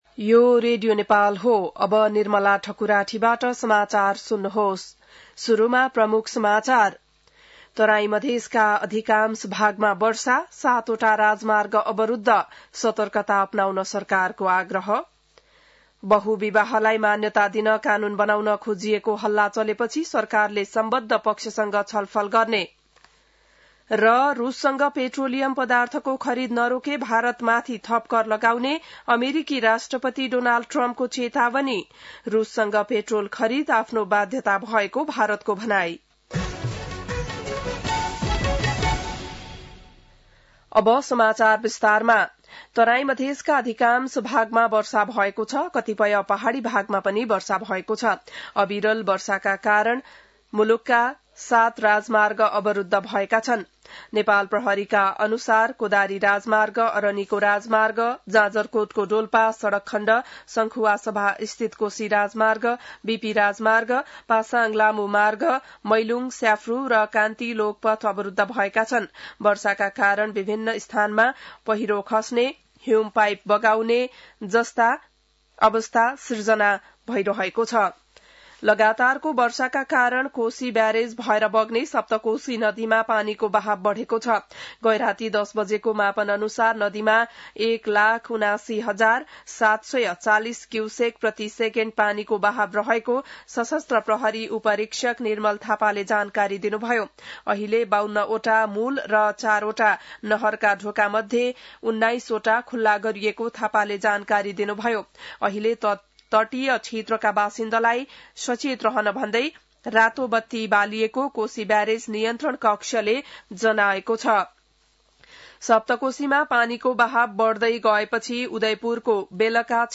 बिहान ९ बजेको नेपाली समाचार : २० साउन , २०८२